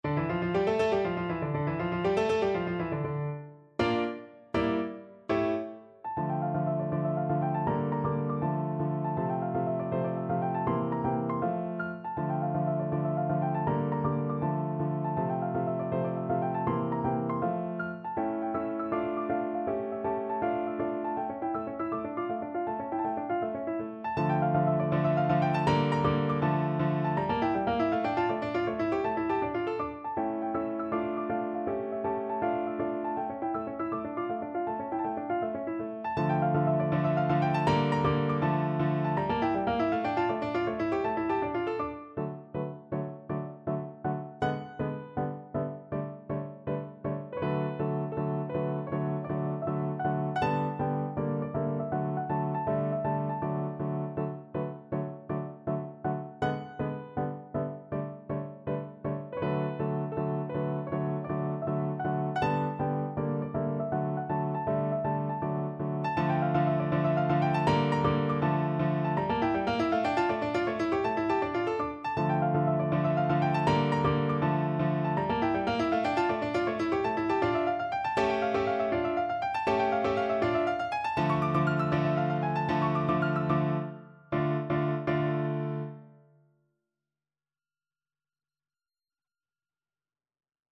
No parts available for this pieces as it is for solo piano.
F major (Sounding Pitch) (View more F major Music for Piano )
Allegro vivo (.=160) (View more music marked Allegro)
6/8 (View more 6/8 Music)
Piano  (View more Intermediate Piano Music)
Classical (View more Classical Piano Music)